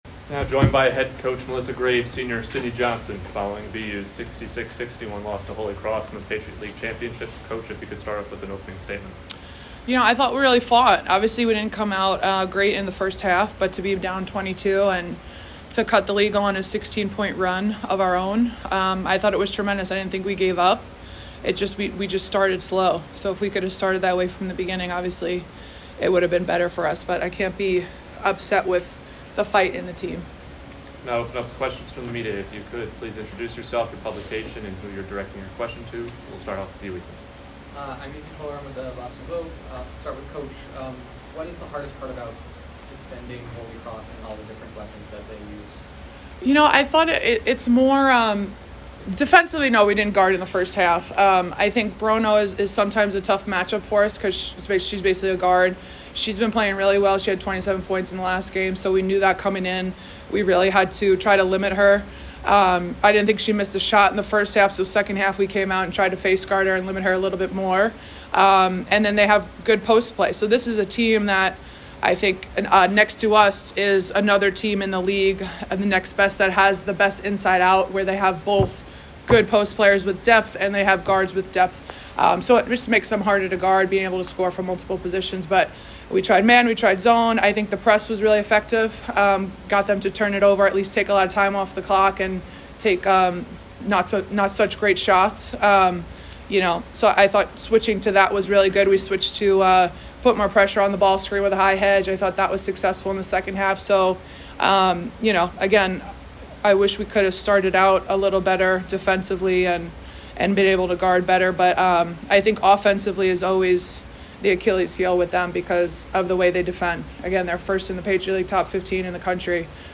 PL Championship Postgame Press Conference
WBB_PL_Champ_Postgame.mp3